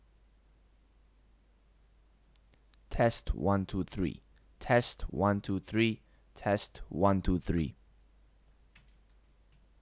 Hissing(white noise) problem with USB microphone
You can hear the white noise in the background but my room is really quiet.
No edits whatsoever, recorded and exported right away.
The microphone noise passes ACX Audiobook by itself, but your voice is low.
The noise is normal and not severe.